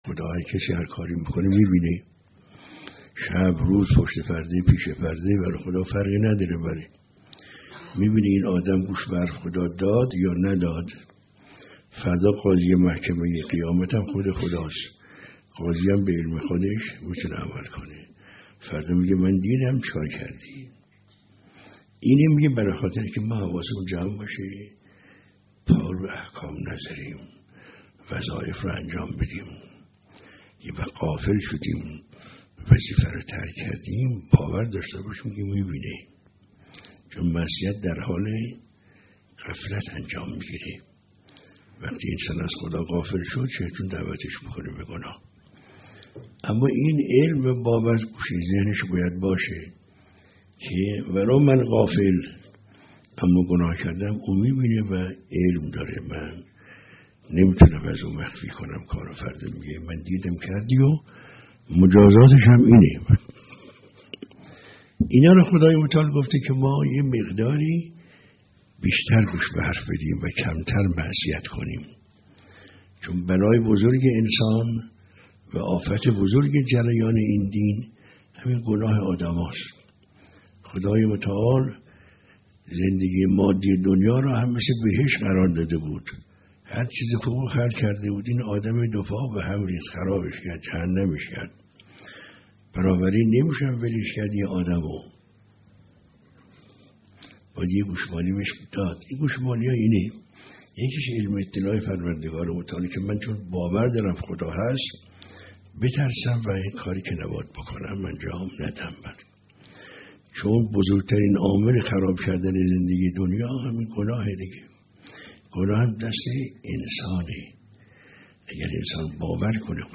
درس اخلاق | بزرگترین عامل بدبختی زندگی انسان + صوت